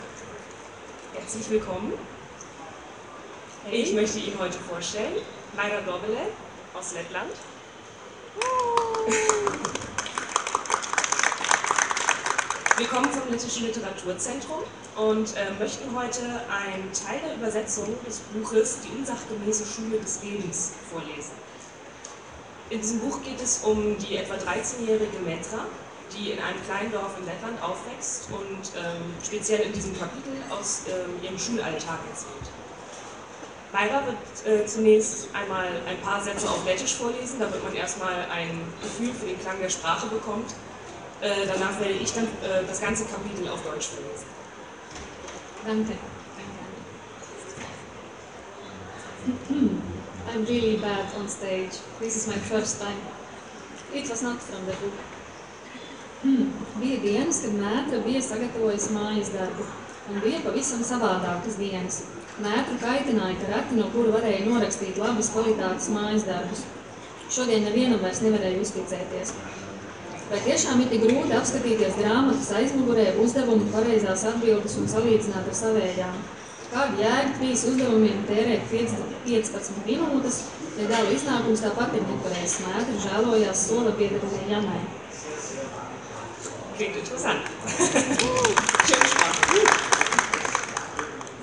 Leipziger Buchmesse 2015